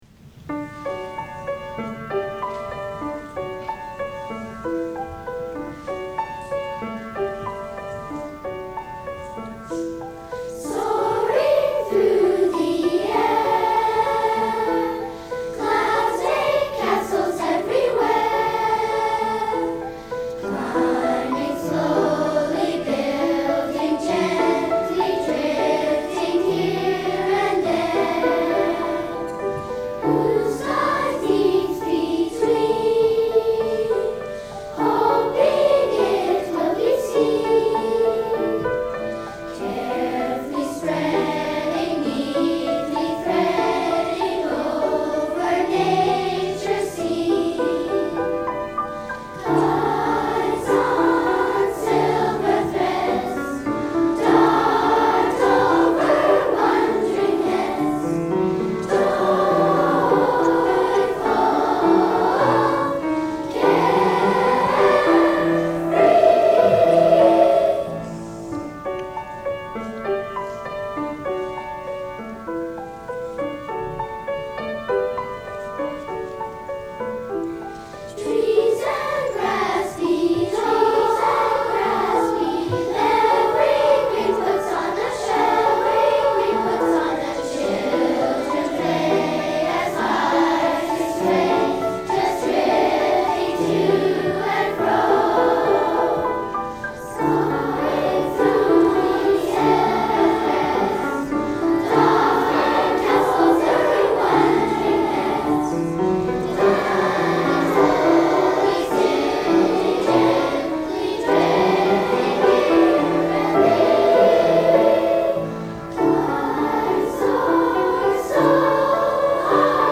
SSA (children) (3 Stimmen).
Genre-Stil-Form: weltlich ; Kinder
Instrumentation: Klavier
Tonart(en): D-Dur